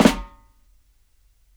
SNARE FLAM SOFT.wav